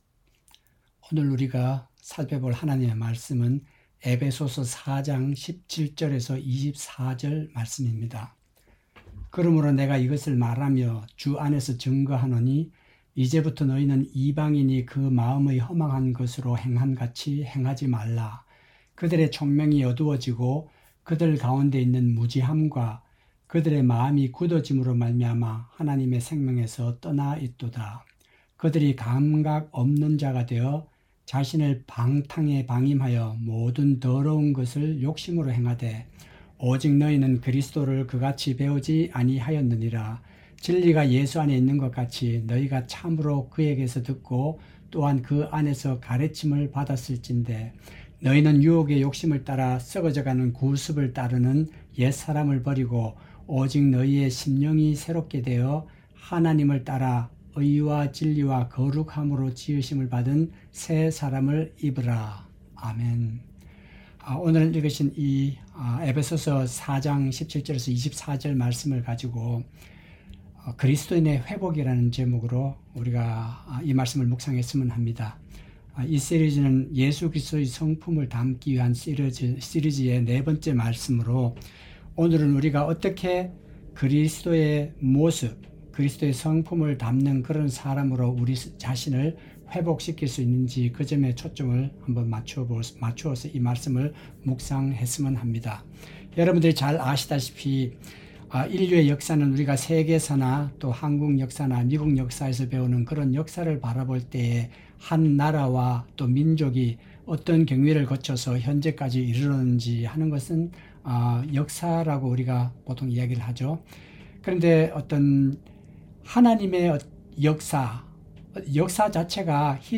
2025년 1월 30일 (목) 새벽 기도회 – 그리스도인의 회복 (엡 4:17-24) – 성품4
새벽설교